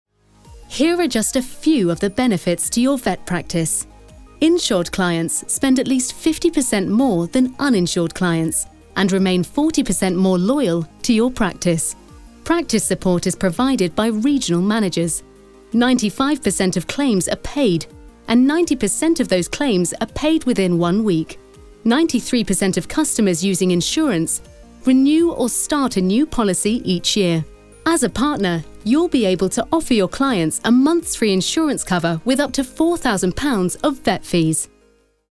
Commercial, Cool, Versatile, Warm
Corporate
Explainer